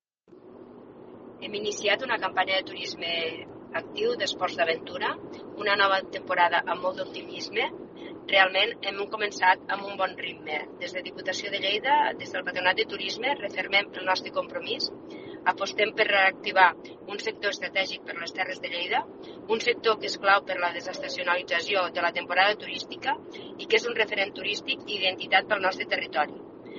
Tall de veu